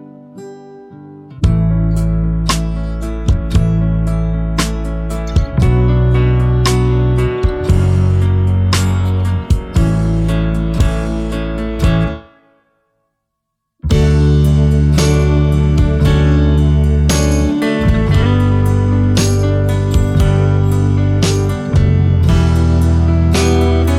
One Semitone Down Pop (2010s) 4:03 Buy £1.50